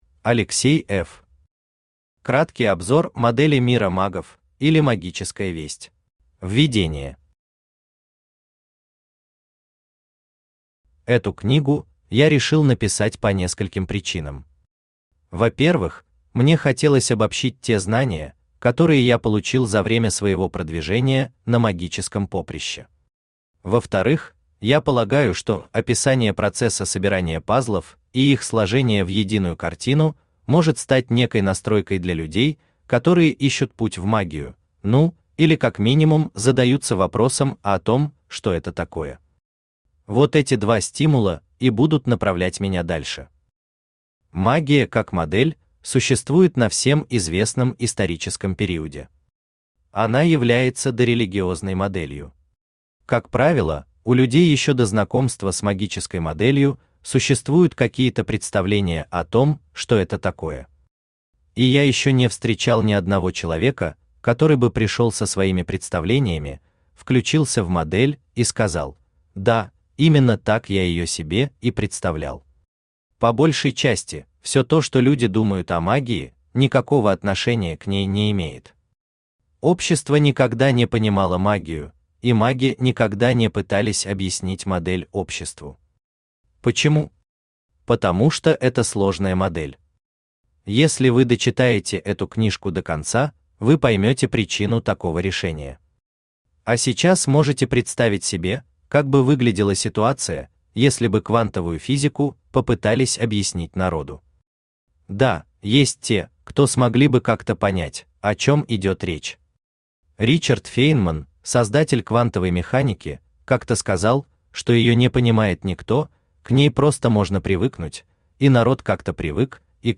Аудиокнига Краткий обзор модели мира магов, или Магическая весть | Библиотека аудиокниг
Aудиокнига Краткий обзор модели мира магов, или Магическая весть Автор Алексей F. Читает аудиокнигу Авточтец ЛитРес.